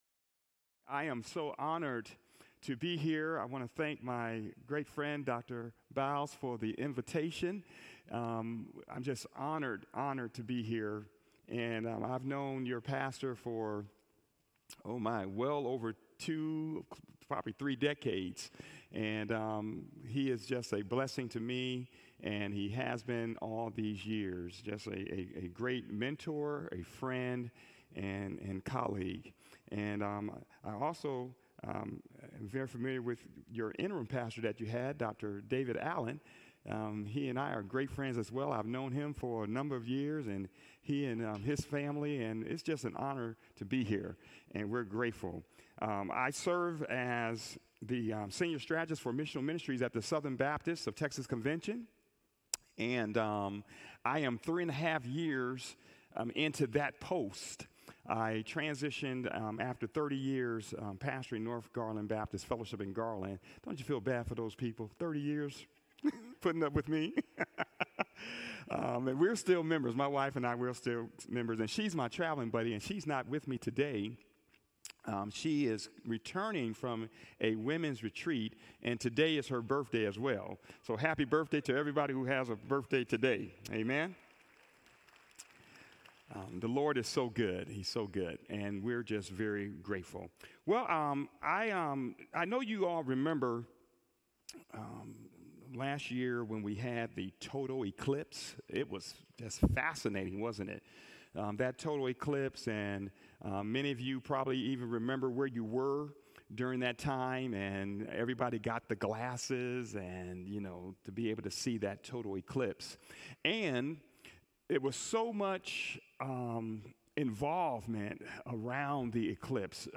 Sermons - Sunnyvale FBC
From Series: "Guest Speaker"